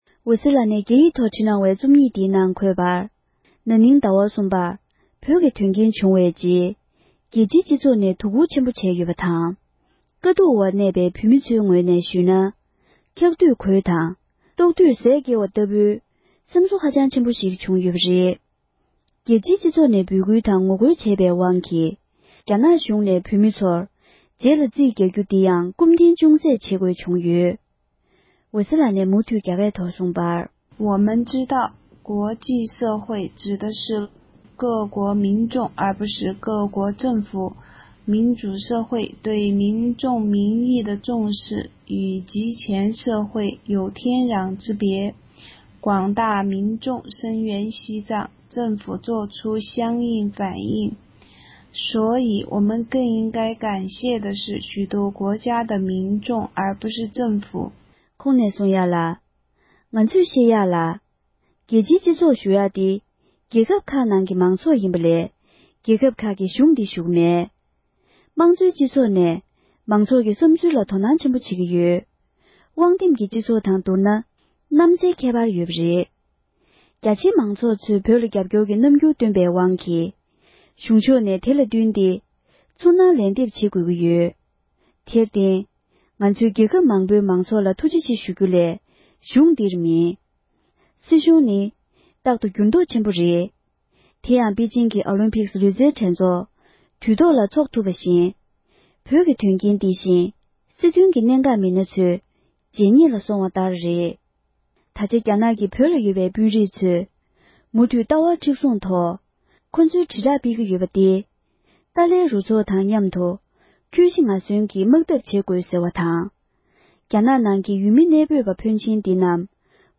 སྒྲ་ལྡན་གསར་འགྱུར།
བོད་སྐད་ཐོག་ཕབ་བསྒྱུར་གྱིས་སྙན་སྒྲོན་ཞུས་པར་གསན་རོགས༎